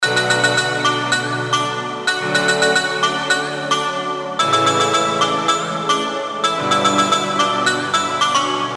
So in this example, we can see the melody is written in “B Minor”.
Melody-Chords.mp3